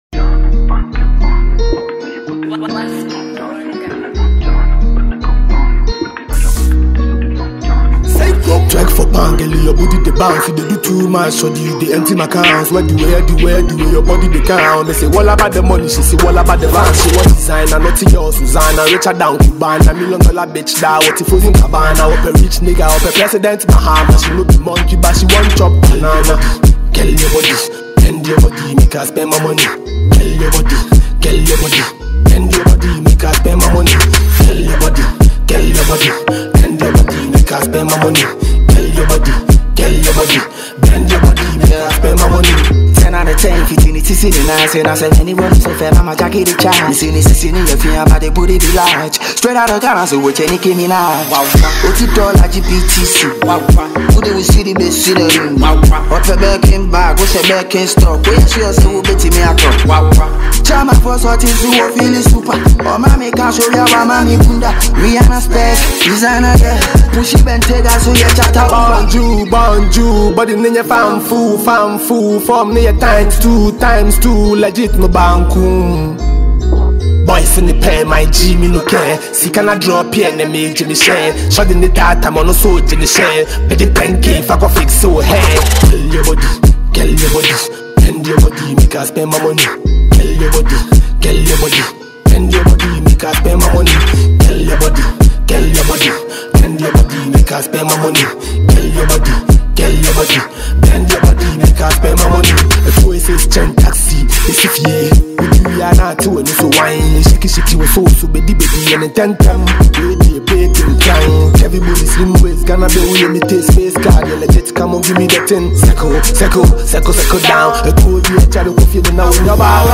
with a thumping beat that’s guaranteed to get you moving.
street-inspired flow
smooth, confident verses